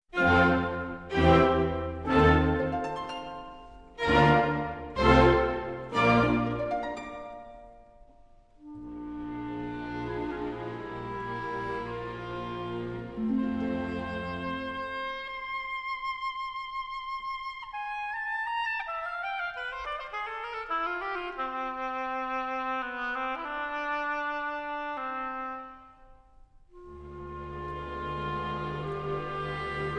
the lyrical oboe in opera and cinema
oboe, oboe d'amore